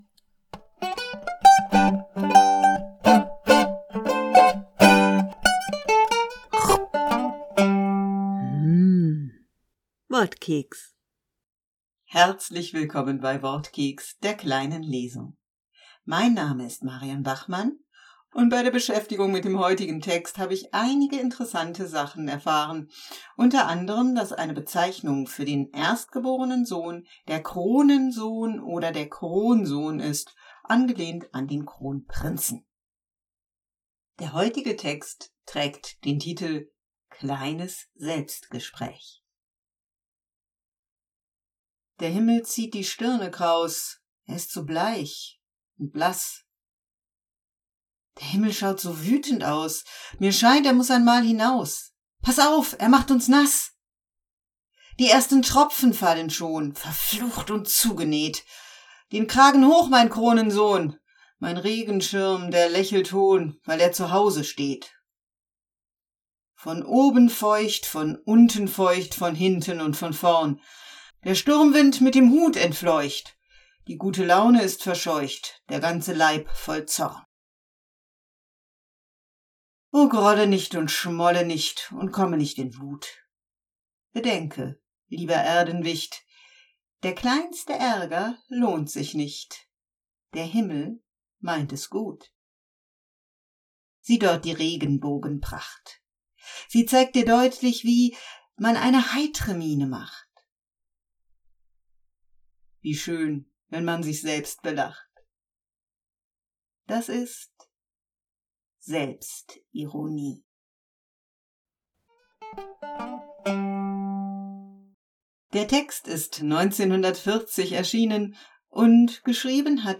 Wortkeks - die kleine Lesung